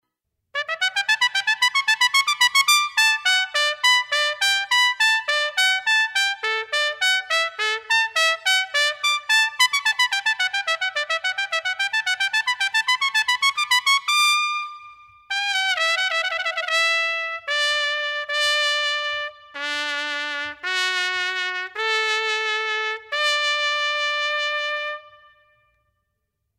Denver brass quintet
The Peak Brass Quintet performs a wide repertoire of classical music.
solo trumpet